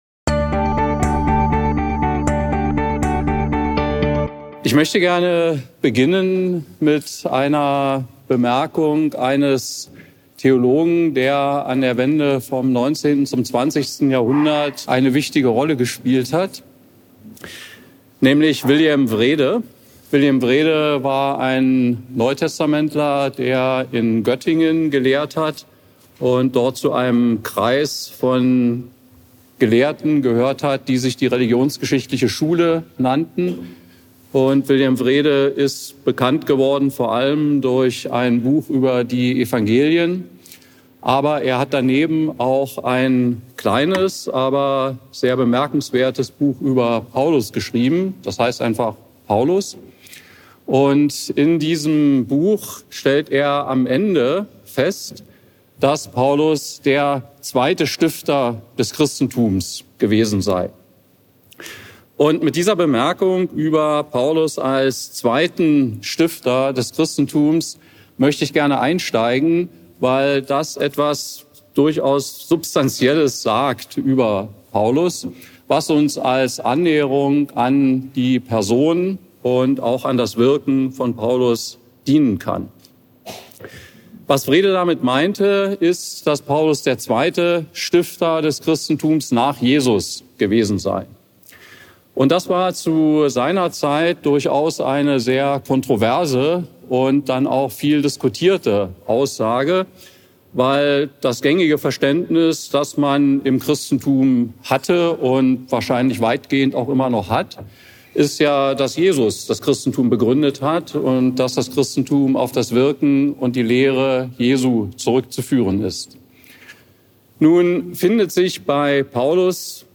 Worthaus 13 – Tübingen: 6.